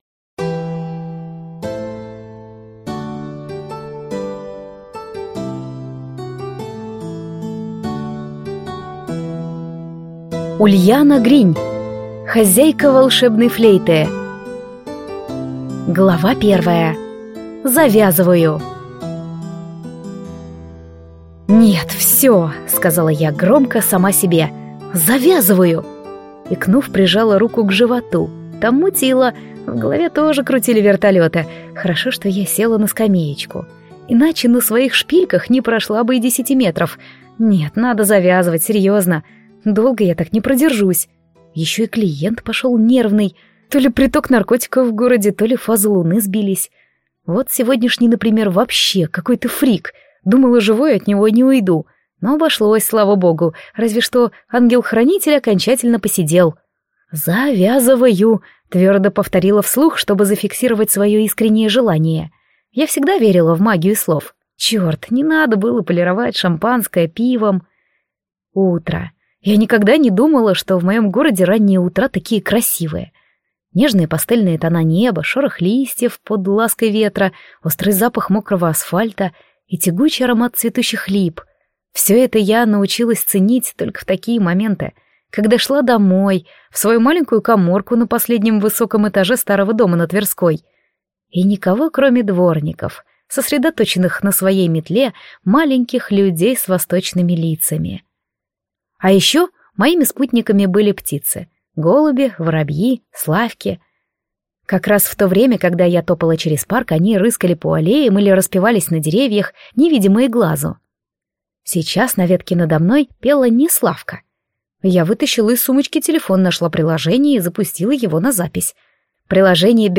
Аудиокнига Хозяйка «Волшебной флейты» | Библиотека аудиокниг